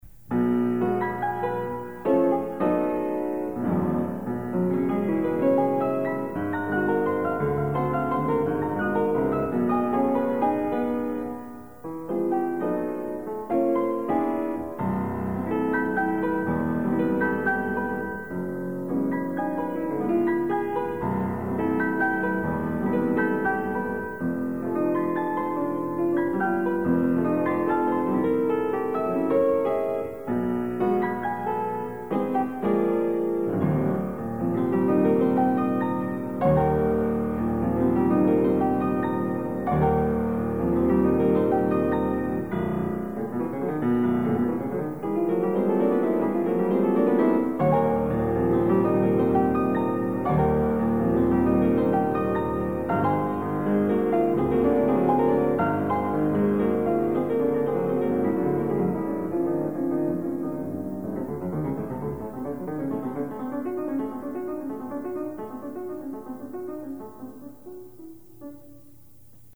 Additional Date(s)Recorded September 11, 1977 in the Ed Landreth Hall, Texas Christian University, Fort Worth, Texas
Etudes
Suites (Piano)
Short audio samples from performance